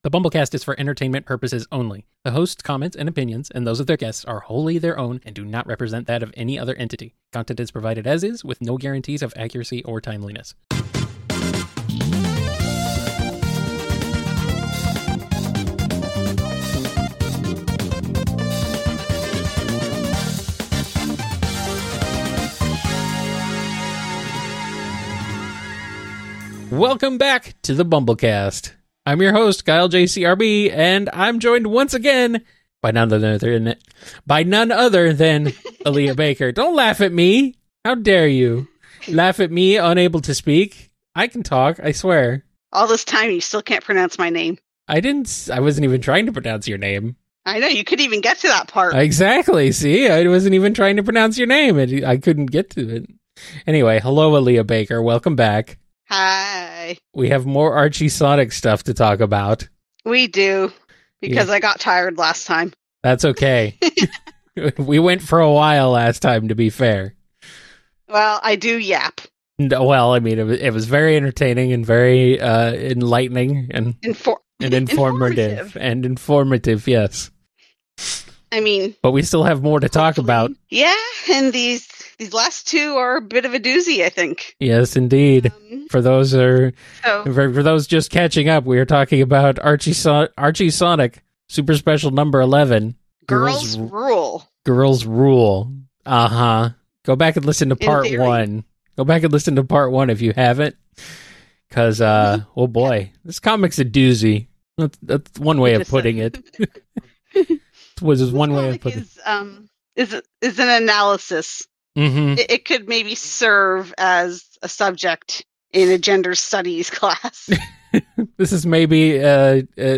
BumbleKast Minis are quick-fire Q&A sessions sponsored by patrons on Patreon, Ko-fi, or YouTube.